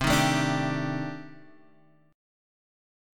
Cm6add9 chord